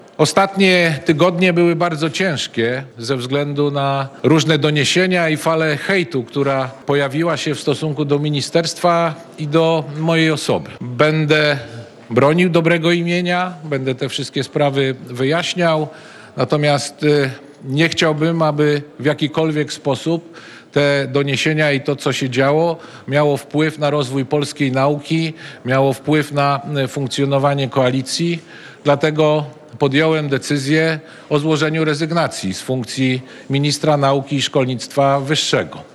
Zachodniopomorski polityk poinformował o tym podczas dzisiejszego briefingu – Podjąłem decyzję o złożeniu dymisji ze stanowiska ministra nauki i szkolnictwa wyższego – oświadczył Dariusz Wieczorek.
KRAJ-Wieczorek-Rezygnacja.mp3